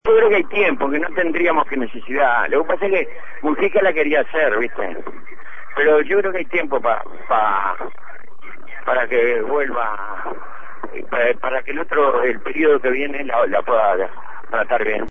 El senador frenteamplista Milton Antognazza, integrante de la comisión, dijo en conversación con El Espectador que no cree que den los tiempos para aprobar el marco regulatorio en este período porque se necesita una discusión profunda